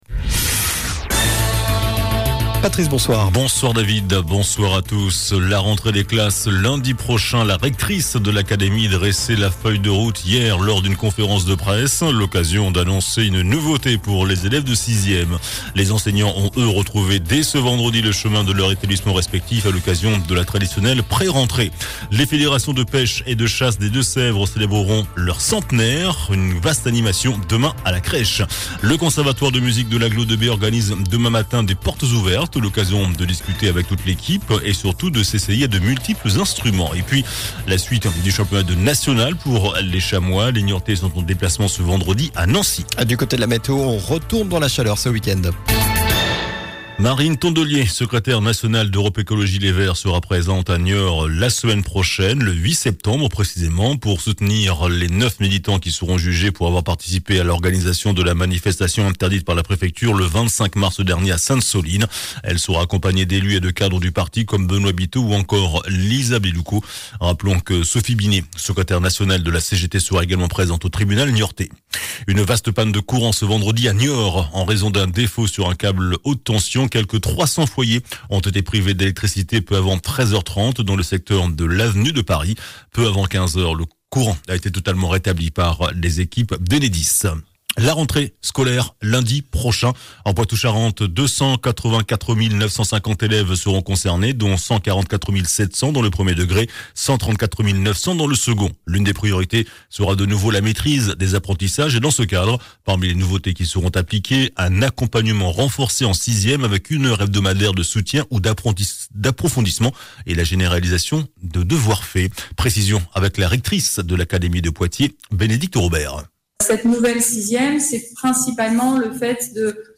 JOURNAL DU VENDREDI 01 SEPTEMBRE ( SOIR )